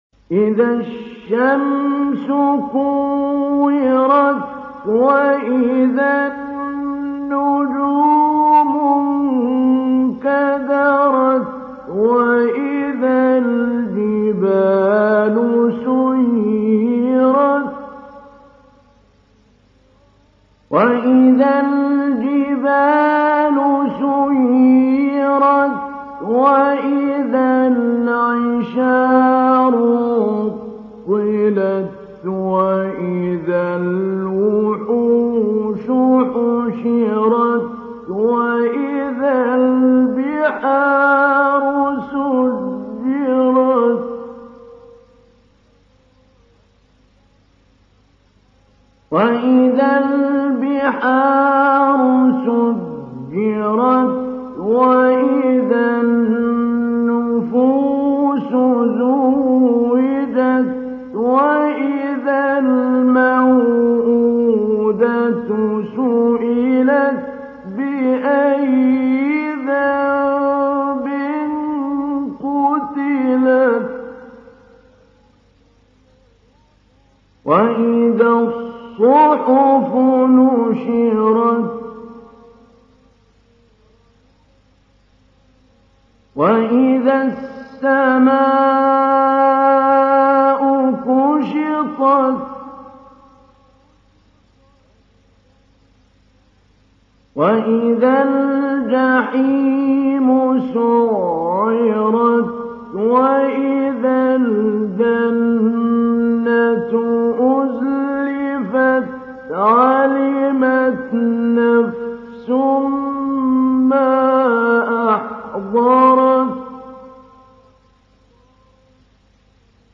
تحميل : 81. سورة التكوير / القارئ محمود علي البنا / القرآن الكريم / موقع يا حسين